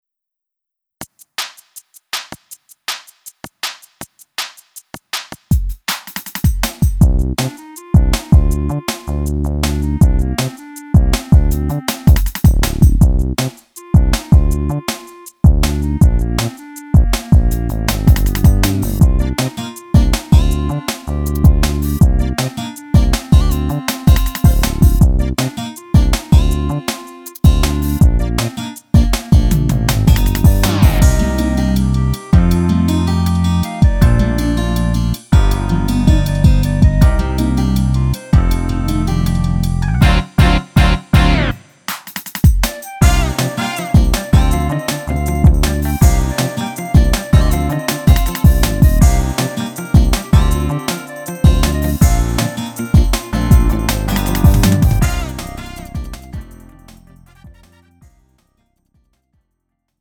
음정 원키 2:30
장르 가요 구분 Lite MR